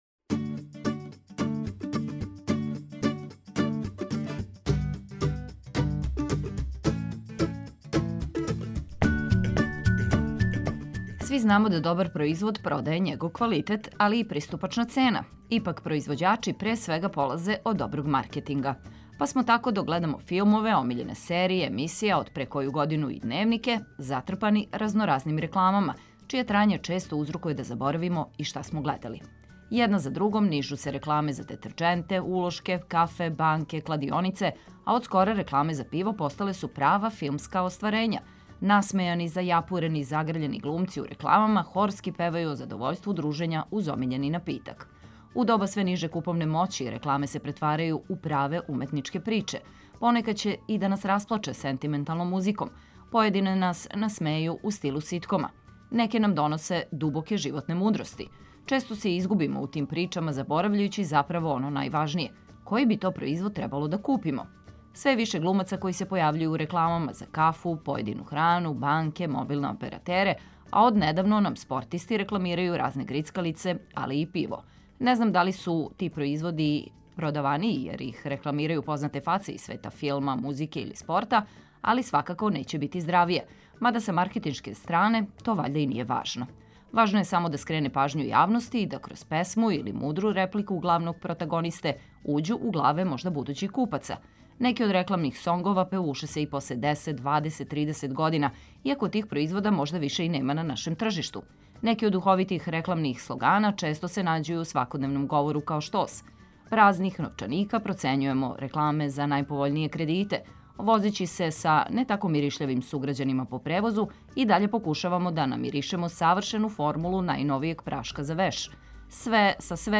Овог 13. понедељка разбудићемо се у ритму познатих хитова, што је добро као почетак.